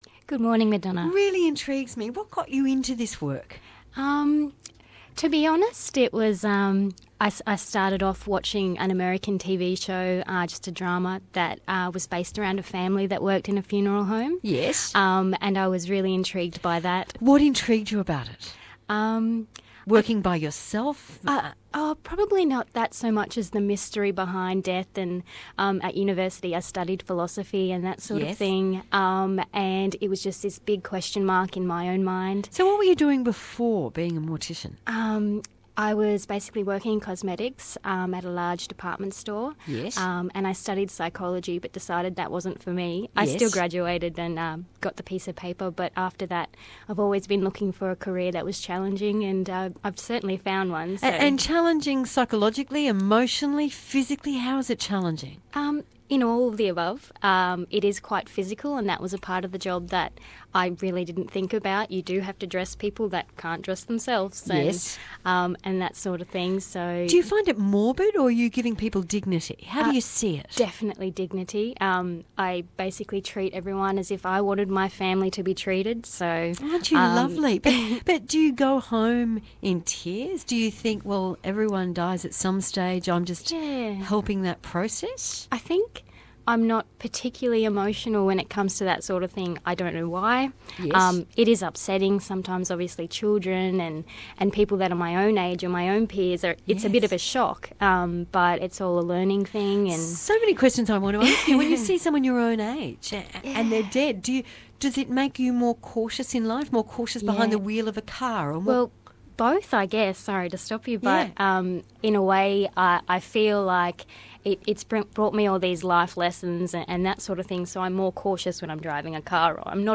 This is an interview by Madonna King, on 612 ABC Brisbane Radio.
mortician.mp3